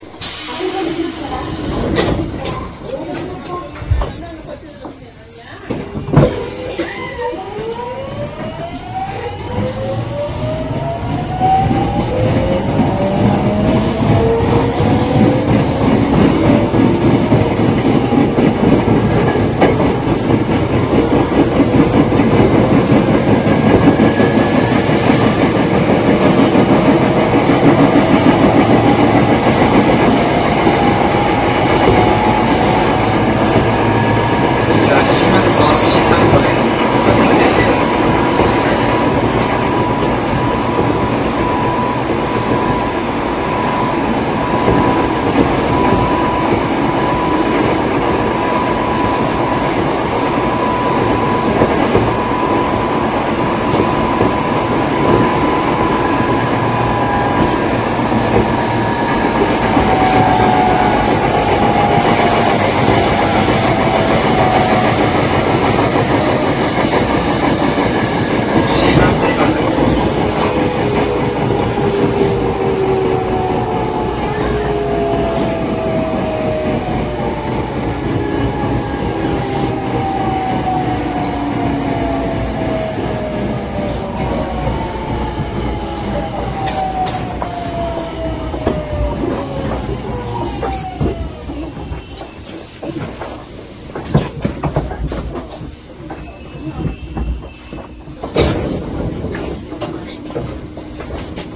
モハ207-903(東芝)[207-903s.ra/198KB]
各車両で大きく音が違うということはありませんが、三菱と日立だけは他の車両と若干音が 違うようです。